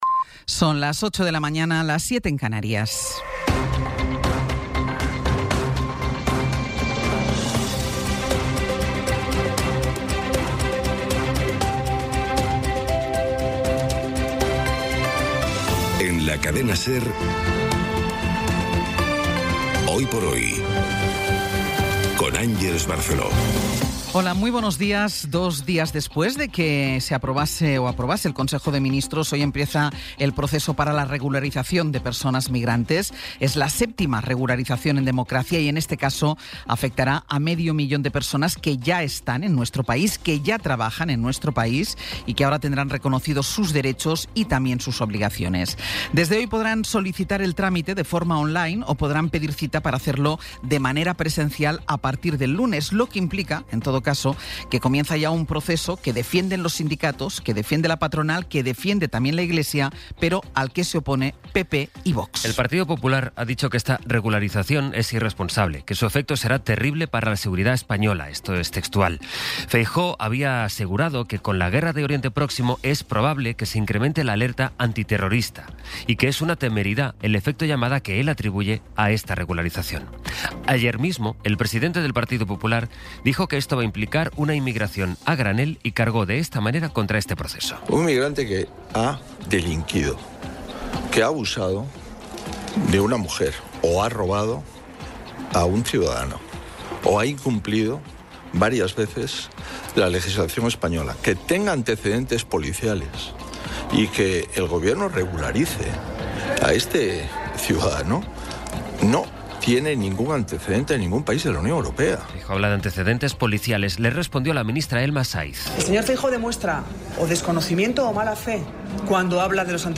Las noticias de las 08:00 20:23 SER Podcast Resumen informativo con las noticias más destacadas del 16 de abril de 2026 a las ocho de la mañana.